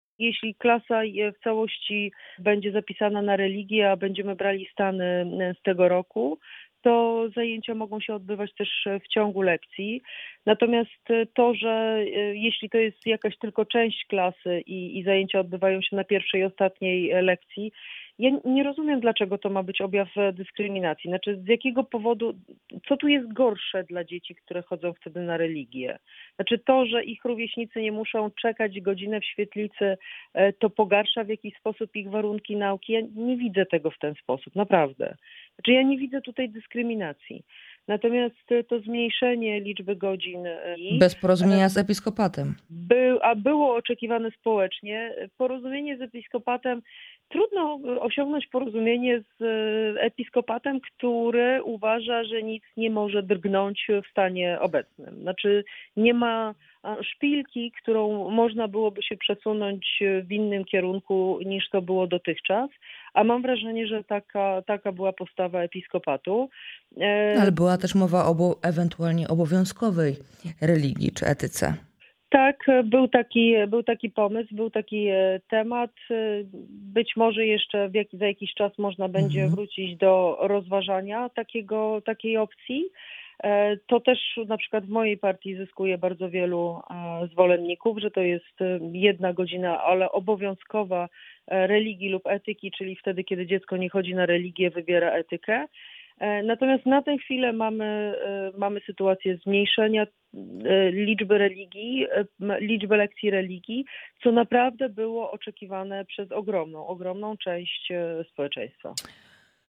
Wiceminister Joanna Mucha w audycji „Poranny Gość” zapowiedziała start kampanii Szymona Hołowni.